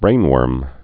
(brānwûrm)